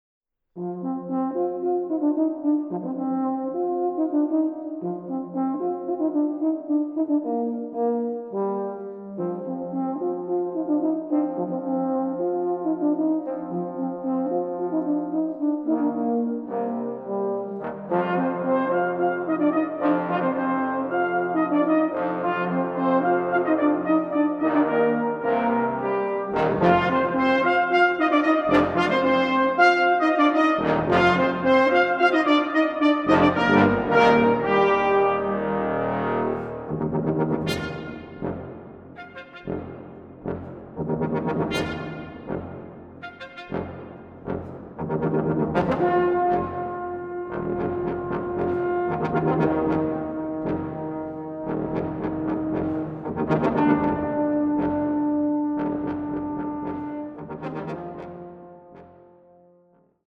Music for trombone choir and brass ensemble
Brass ensemble